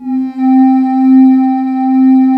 Index of /90_sSampleCDs/USB Soundscan vol.28 - Choir Acoustic & Synth [AKAI] 1CD/Partition D/26-VOCOSYNES